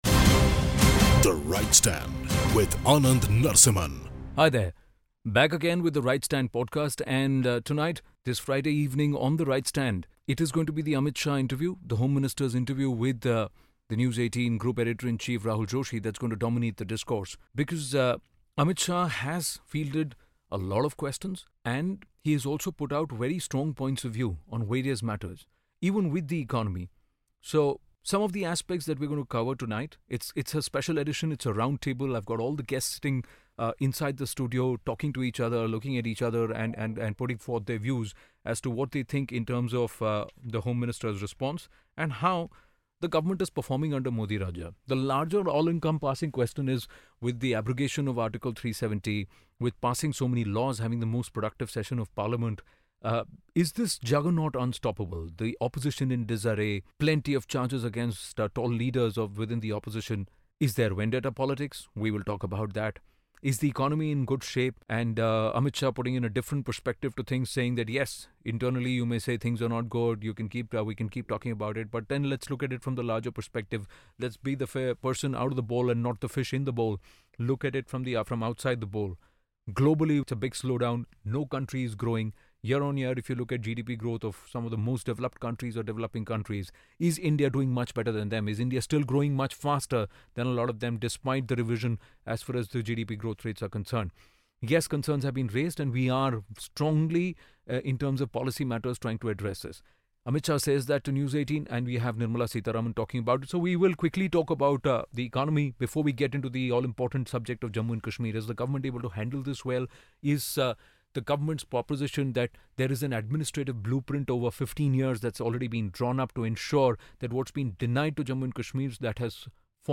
Amit Shah's in an interview with CNN-News18 talks about everything from Economy to Section 370 to Vendetta politics. Is the BJP unstoppable in Modi Rajya?